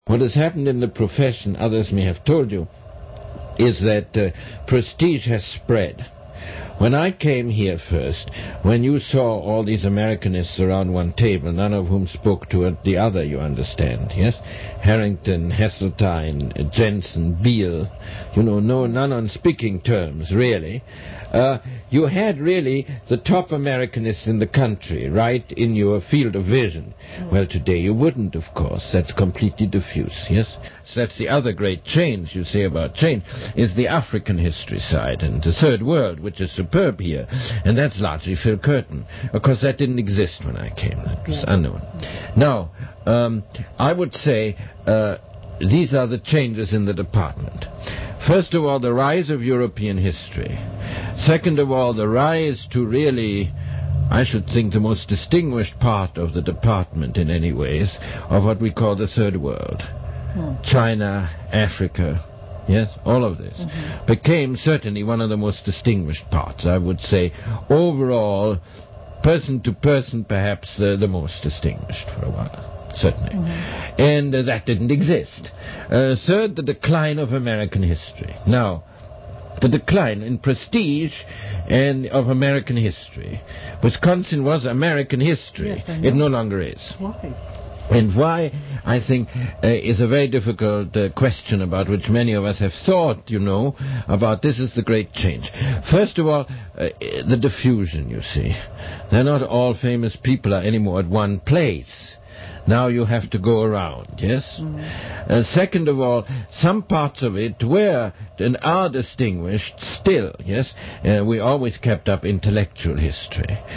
Recording, oral
UW-Madison Oral History Program